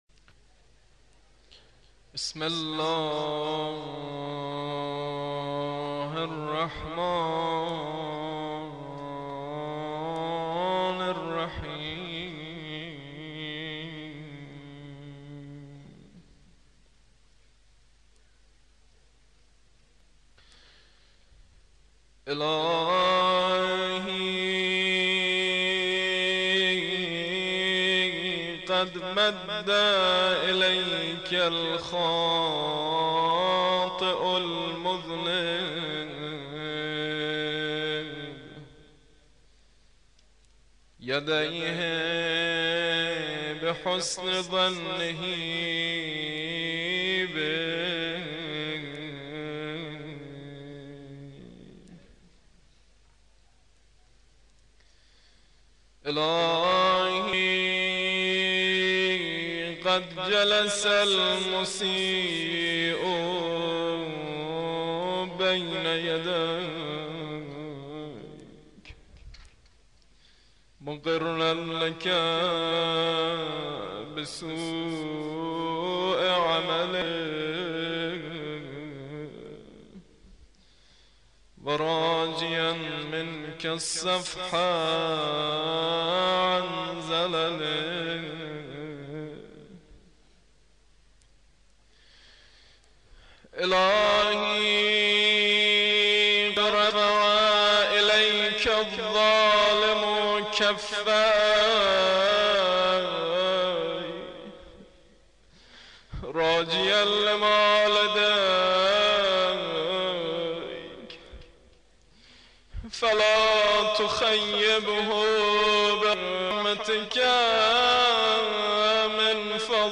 مجموعه صوت‌ها و قرائت‌های مناجات، ادعیه و زیارات
قرائت مناجات خمس عشر«مناجات الزاهدین»